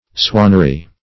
swannery - definition of swannery - synonyms, pronunciation, spelling from Free Dictionary
Search Result for " swannery" : The Collaborative International Dictionary of English v.0.48: Swannery \Swan"ner*y\, n. A place where swans are bred.